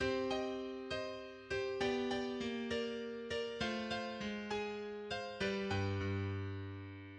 It is often used in music to denote tragedy or sorrow.[3]
Lament bass from Vivaldi's motet "O qui coeli terraeque serenitas" RV 631, Aria No. 2[5]